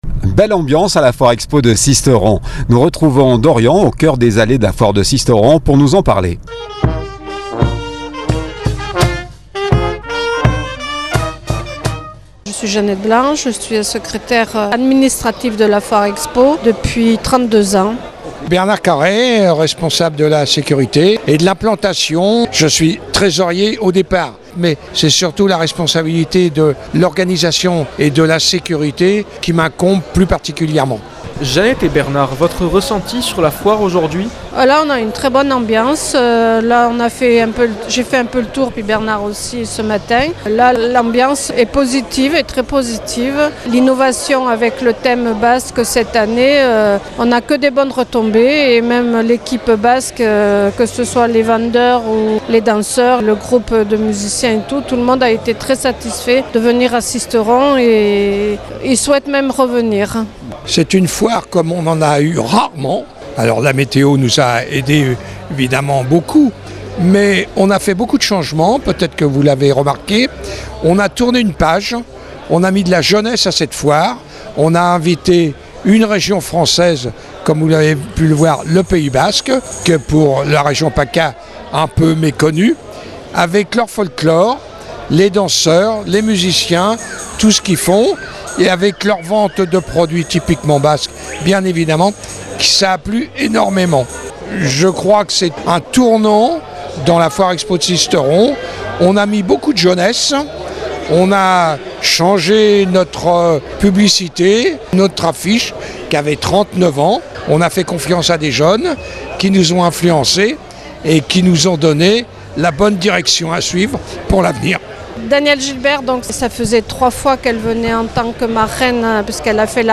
Belle ambiance au cœur de la Foire Expo de Sisteron